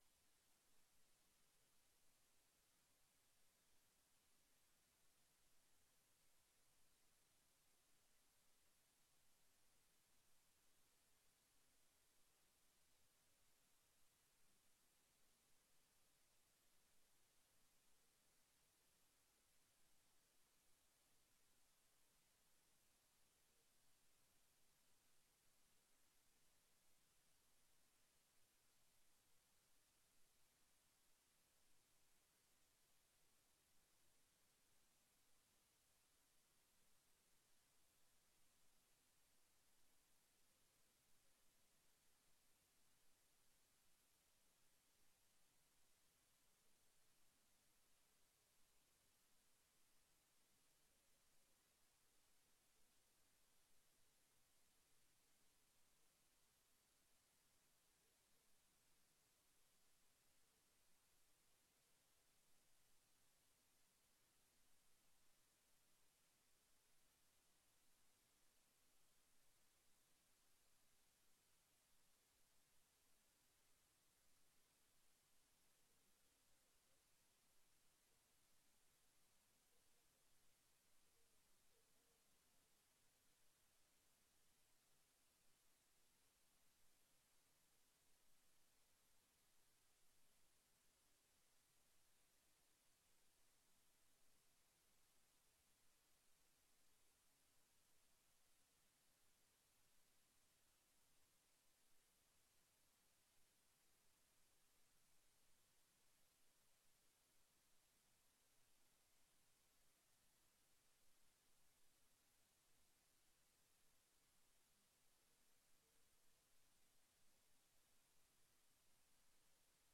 Inspreker: Ondernemersvereniging Binnenstad Venlo
Stadhuis Parterre